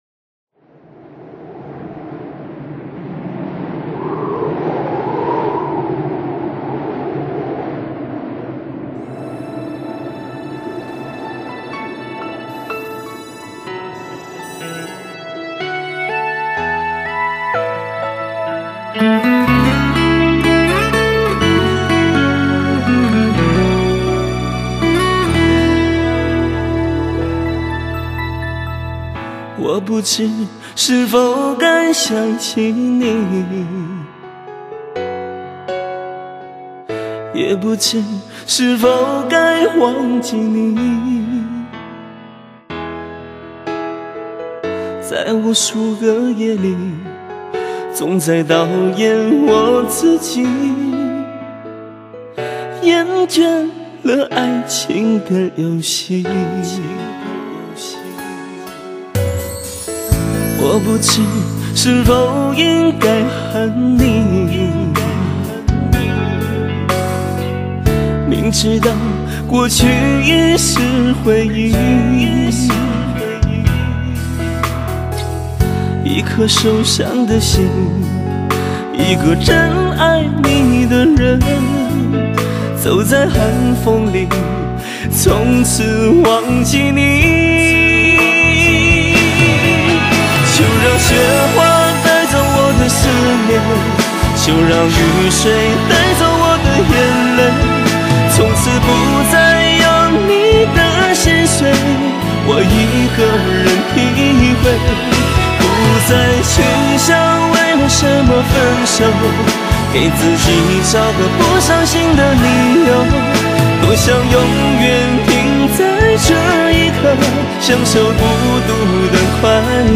歌曲延续了伤感的曲风，但很真挚。